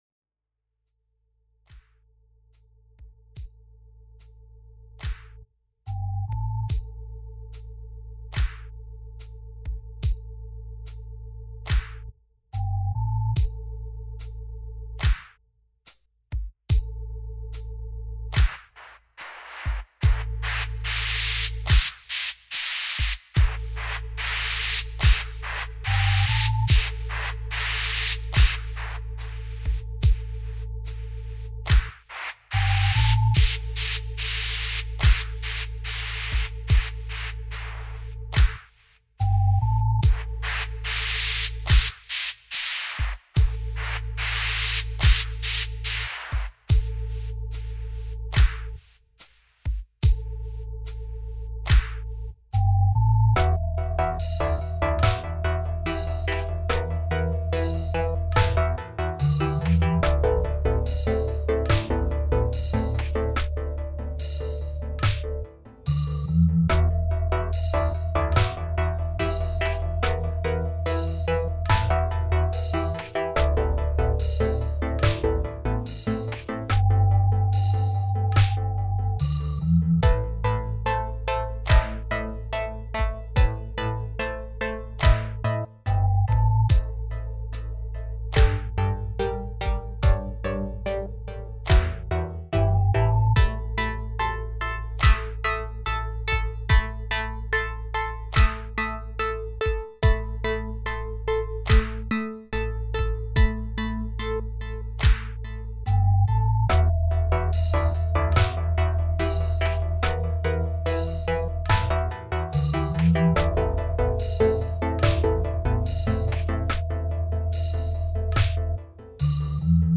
brighter and warmer stufff